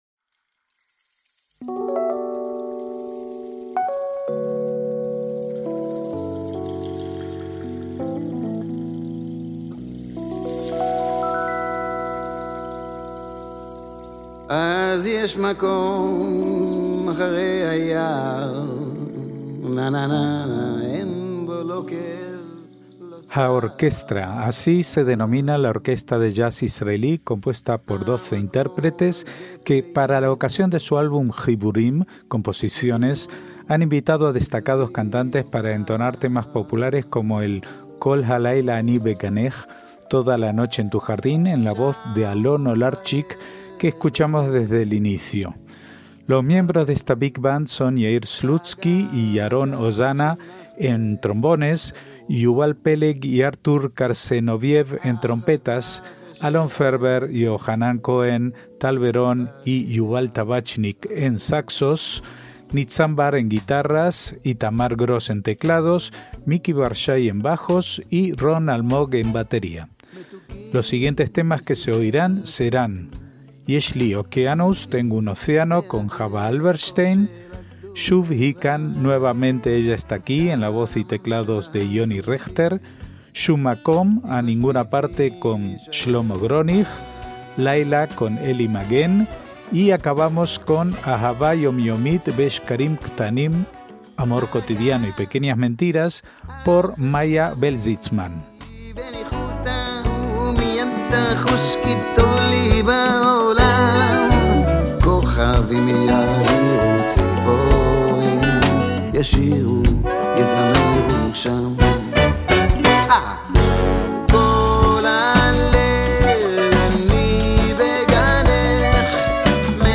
MÚSICA ISRAELÍ
orquesta de jazz israelí compuesta por 12 intérpretes
Big Band
trombones
trompetas
saxos
guitarras
teclados
bajos
batería